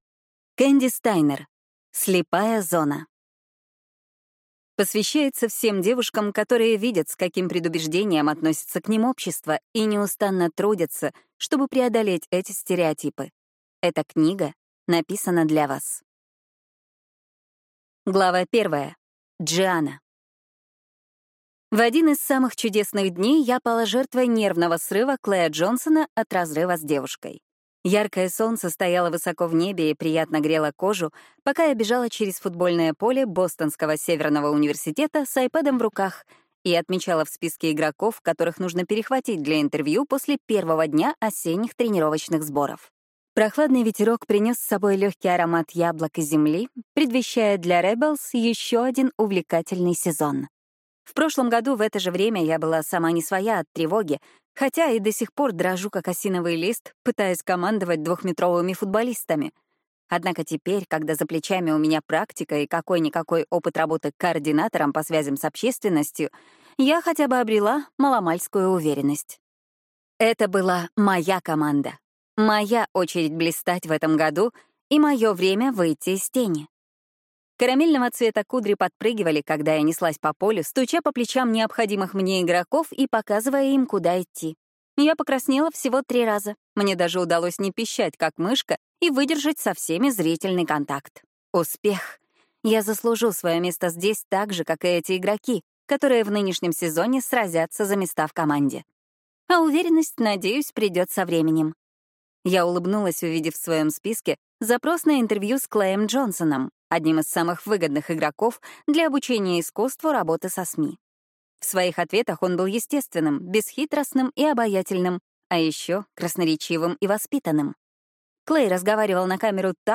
Аудиокнига Слепая зона | Библиотека аудиокниг